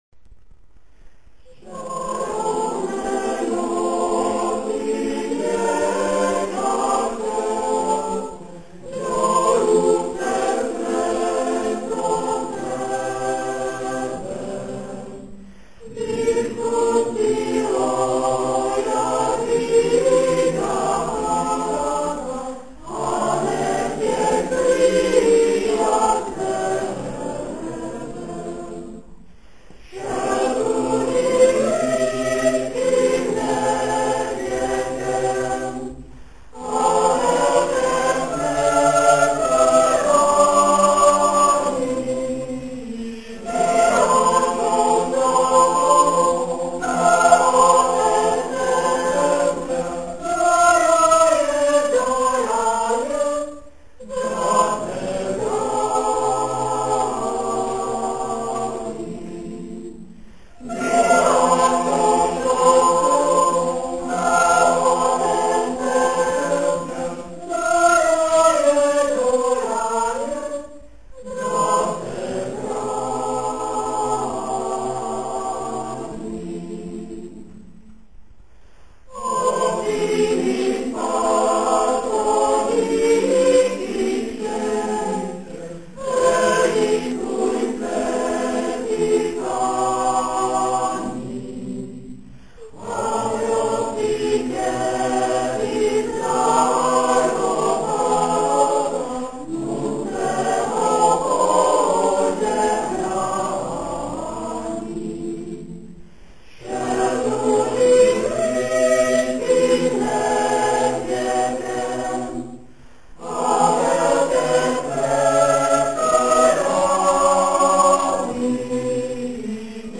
25. komorní koncert na radnici v Modřicích
II. část koncertu - Smíšený pěvecký sbor města Modřice
Ukázkové amatérské nahrávky WMA: